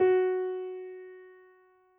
piano_054.wav